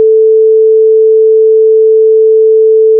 Son diapason
Son pur diapason.wav